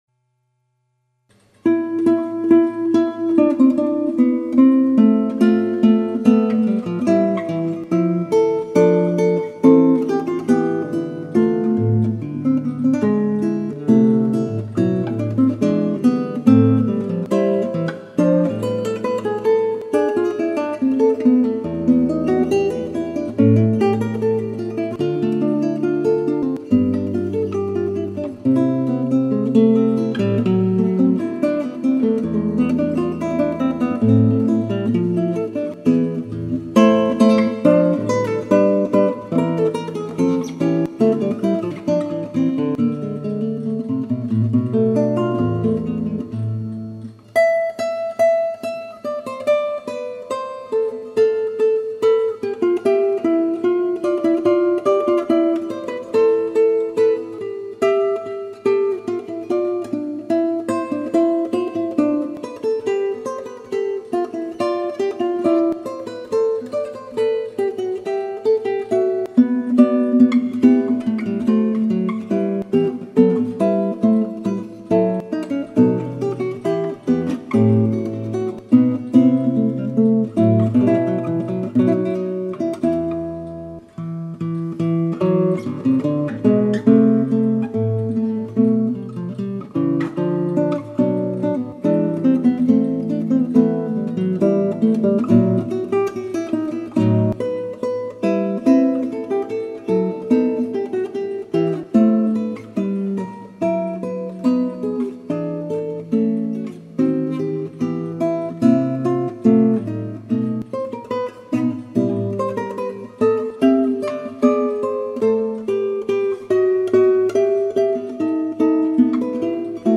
This piece is the transcription for guitar by N. Alfonso from the version for solo violin.
Bản soạn lại cho tây ban cầm do N. Alfonso chuyển qua cung La thứ.
Thể loại nhạc Fugue mang tên này v́ có các bè đuổi nhau, và người nghe có cảm tưởng như mỗi bè “bỏ chạy” khi bị bè khác đuổi.
Bach_Fugue-Am.mp3